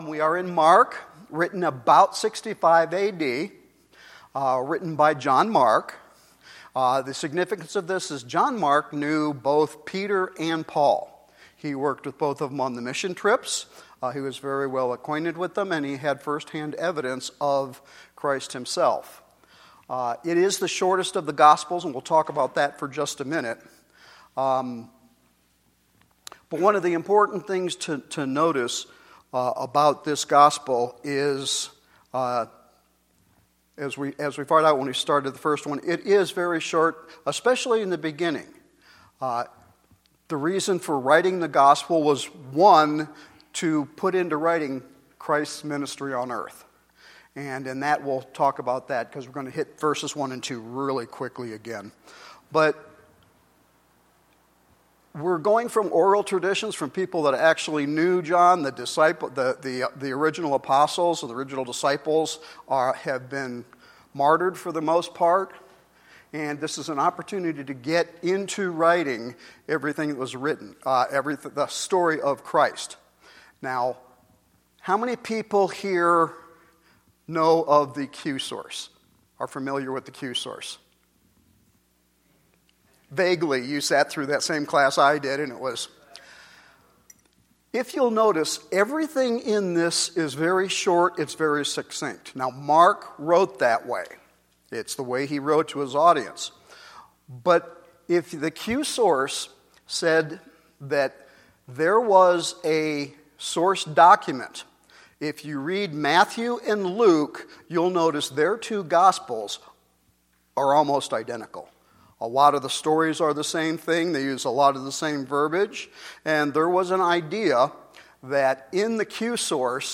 Our new Sunday evening Bible study continues with Mark Chapter 1.